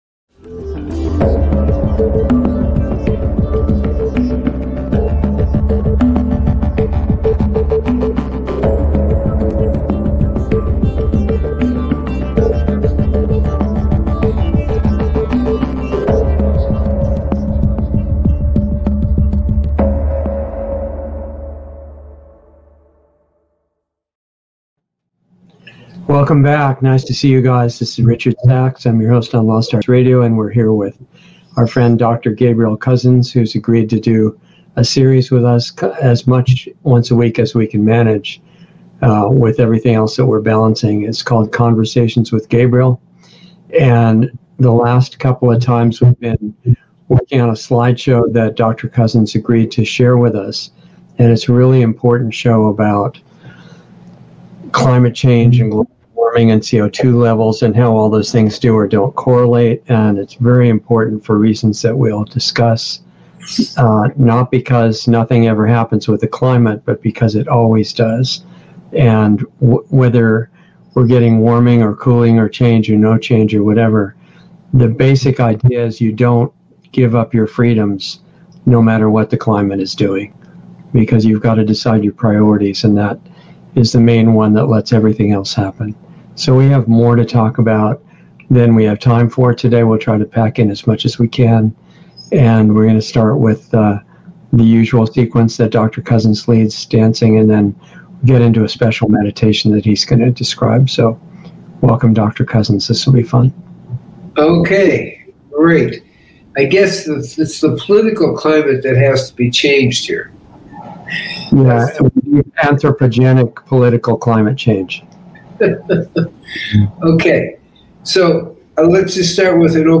Lost Arts Radio Live - Conversations